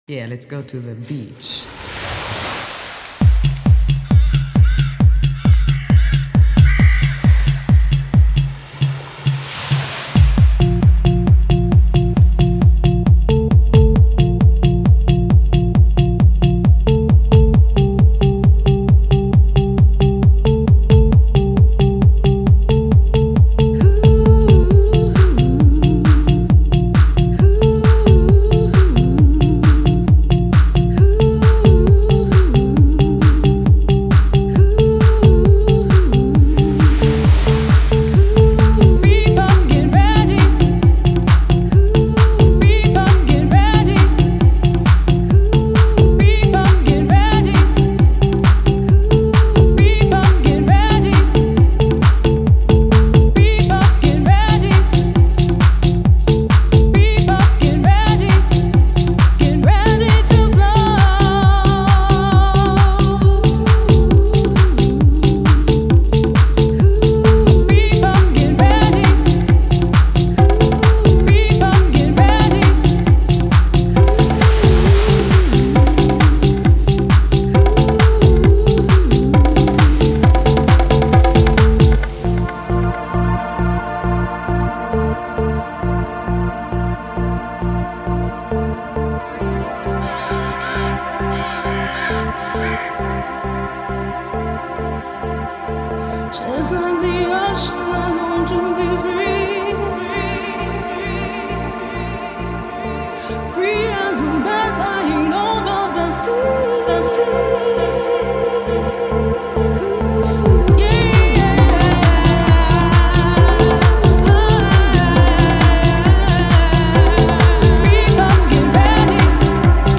Dream Dance